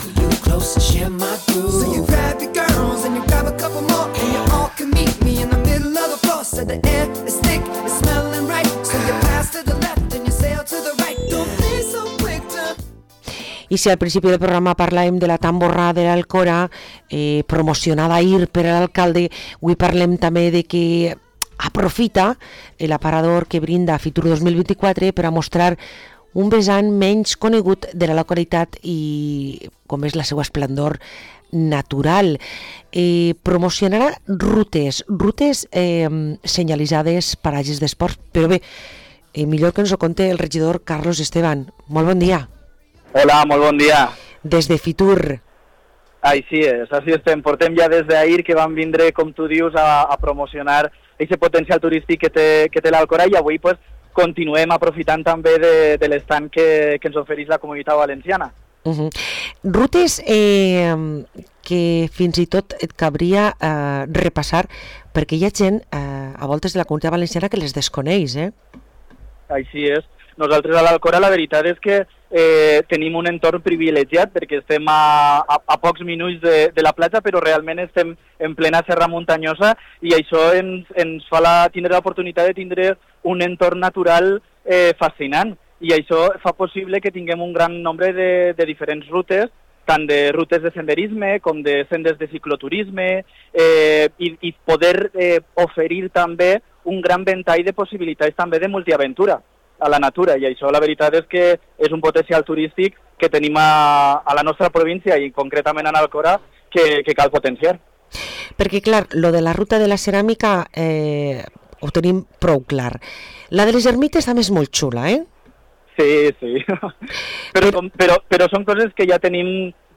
Parlem amb Carlos Esteban, regidor de cultura, turisme i patrimoni a l´Alcora